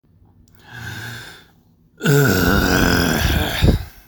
I make the following sound, recreated and saved here for posterity.
Disgust.mp3